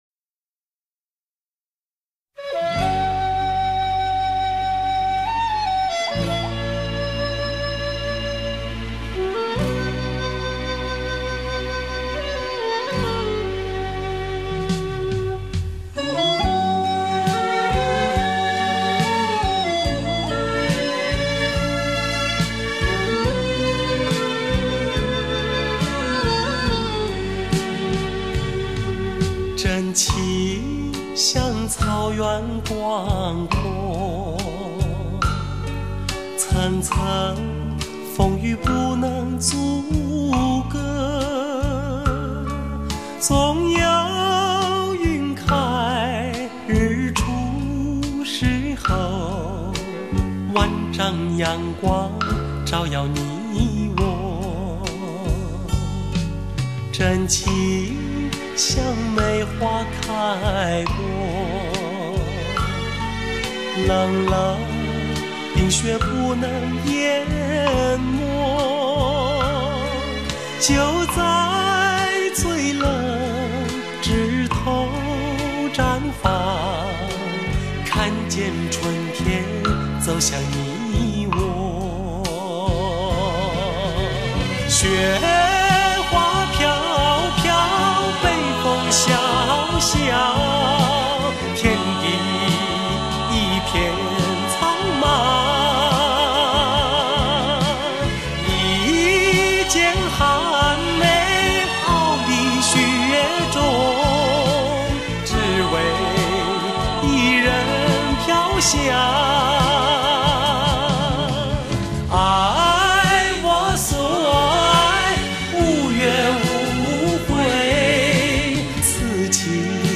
黑胶唱片3CD
声色淳朴的醉人嗓音，非同凡响的淳朴演绎。